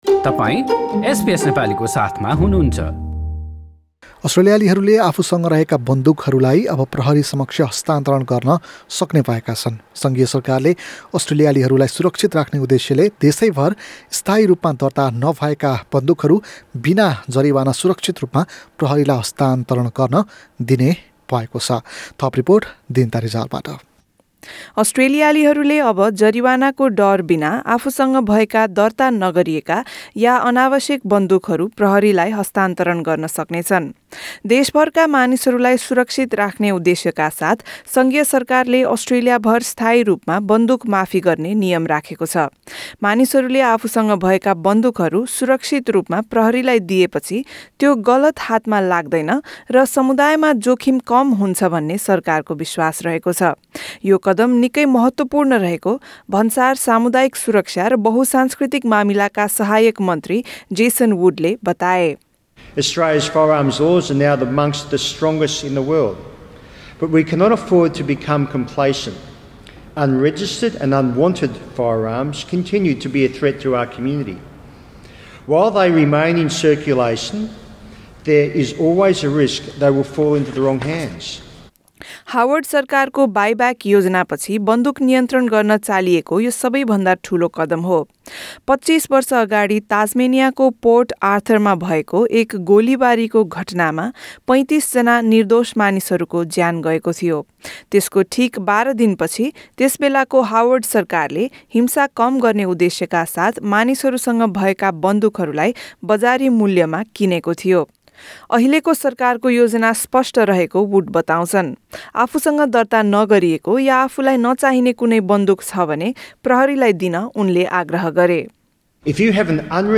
रिपोर्ट।